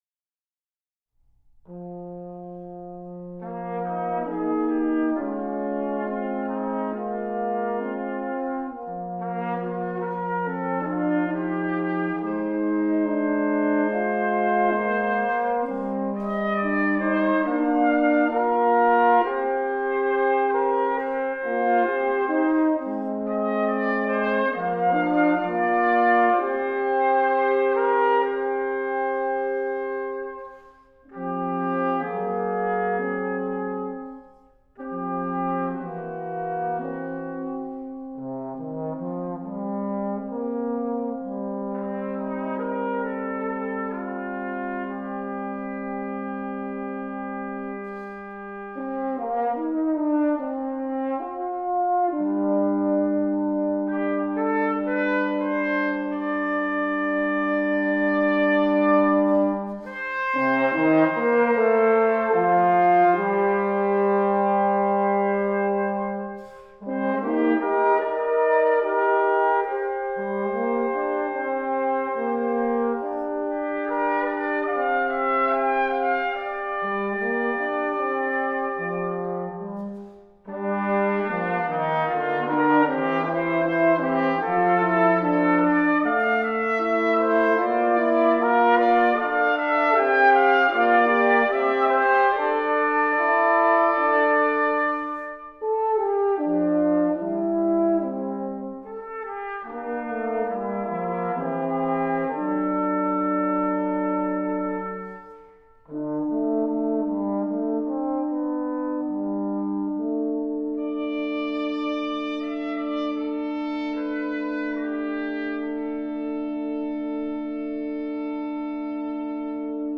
Partitions pour trio flexible.